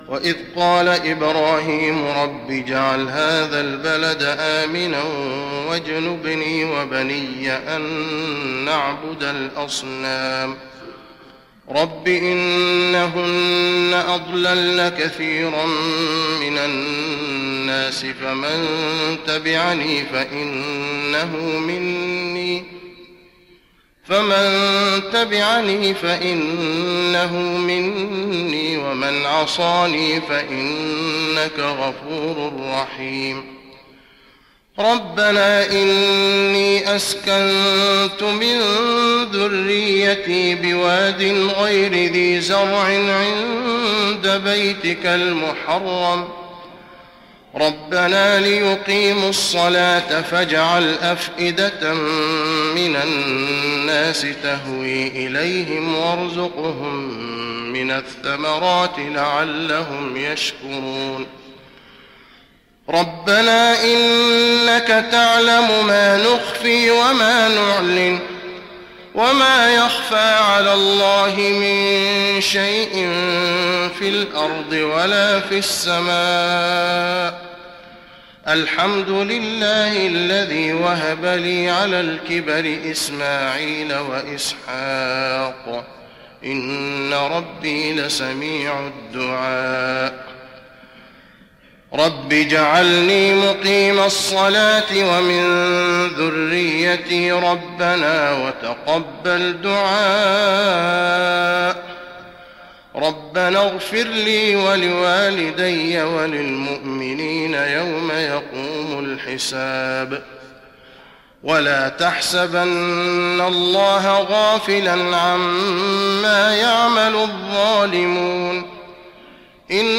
تراويح رمضان 1415هـ من سورة إبراهيم (35-52) والحجر والنحل (1-40) Taraweeh Ramadan 1415H from Surah Ibrahim to Surah An-Nahl > تراويح الحرم النبوي عام 1415 🕌 > التراويح - تلاوات الحرمين